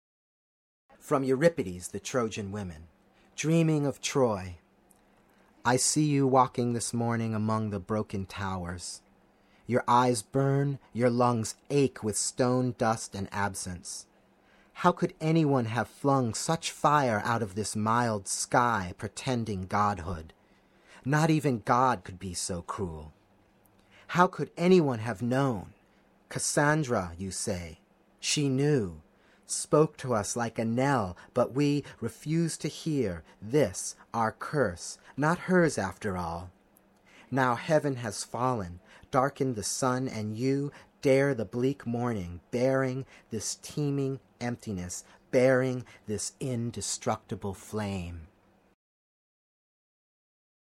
We recorded these poems shortly after the events discribed.